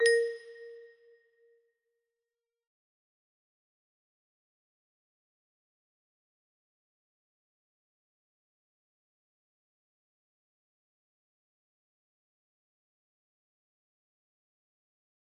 a single note music box melody
theres a single note in this "song"